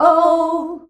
OUUH  E.wav